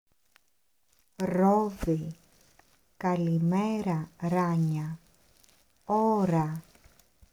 Le fameux ρ roulé : un petit défi pour la langue !
Le ρ grec est plus léger, plus “claquant”. Il se prononce avec le bout de la langue qui vibre contre le palais. C’est un "r roulé".
• ρόδι (grenade) → [ródi], pas [ʁodi]
• ώρα (heure) → [óra], pas [oʁa]